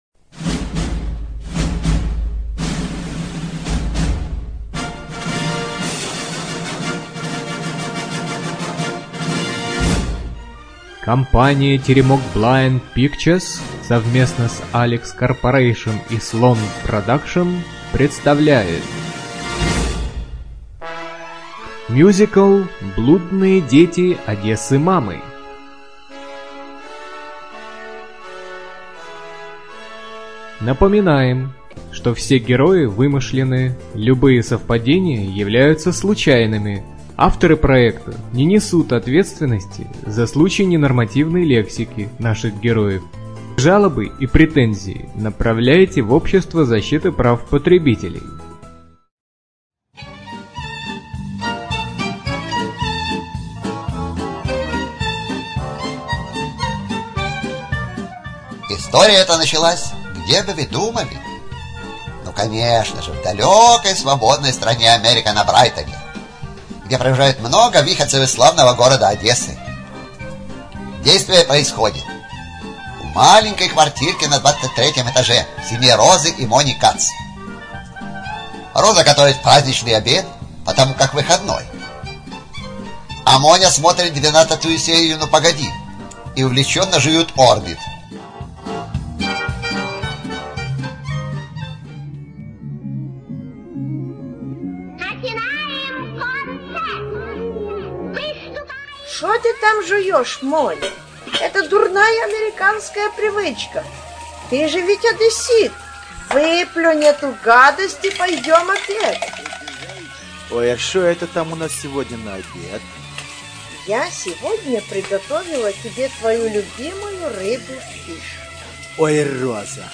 Студия звукозаписиТеремок 4